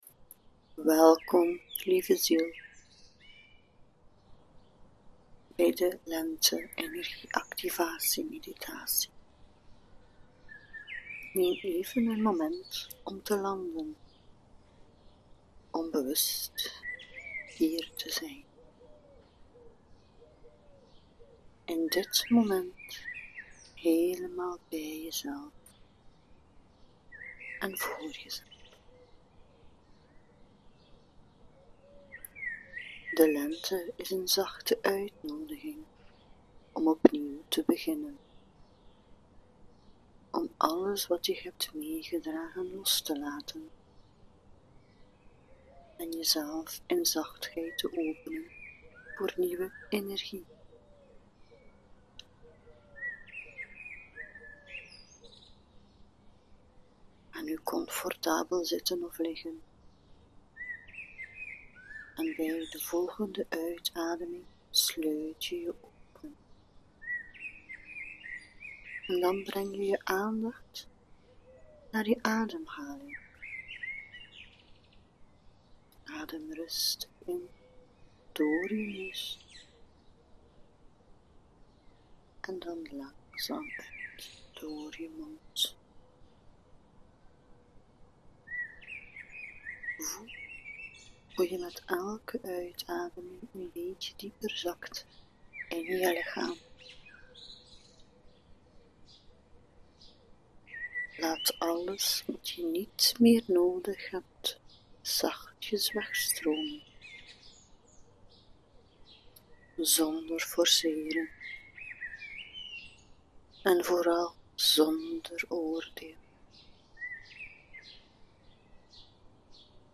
Lente energie activatie meditatie